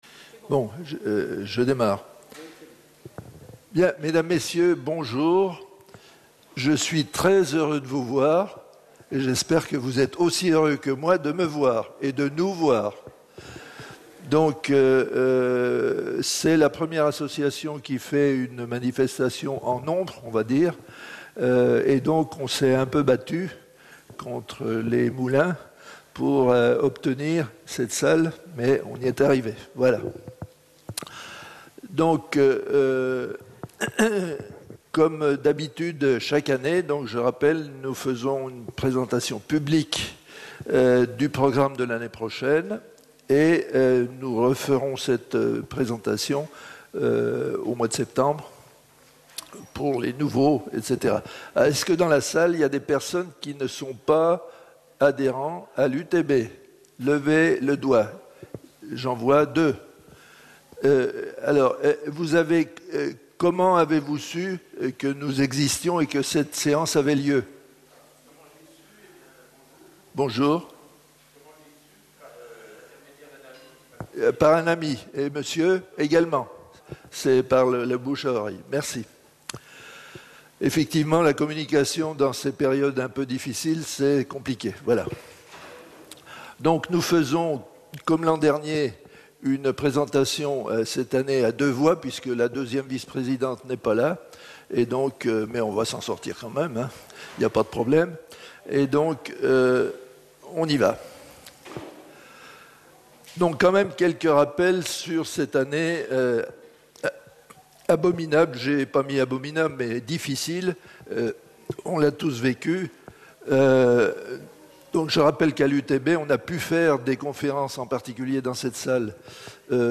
Présentation publique du programme des conférences 2021-2022